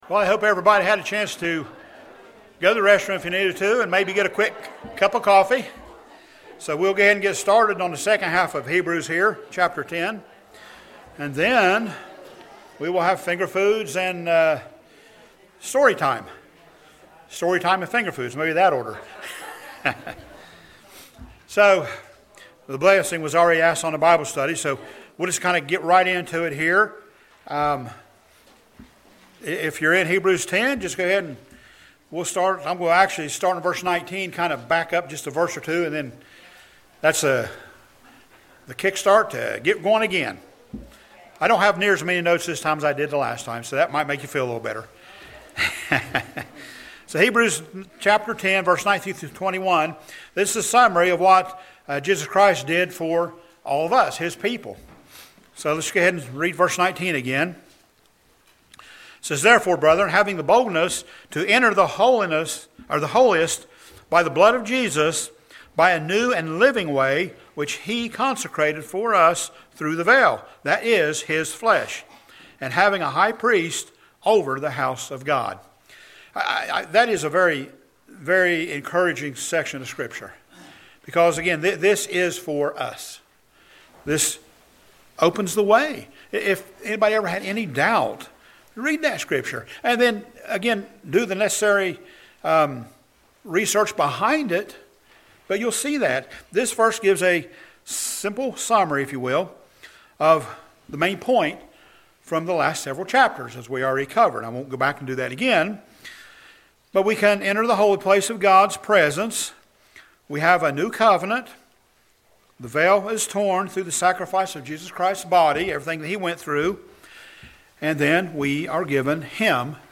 In this Bible Study we will cover verses 21-38.